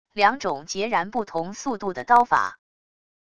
两种截然不同速度的刀法wav音频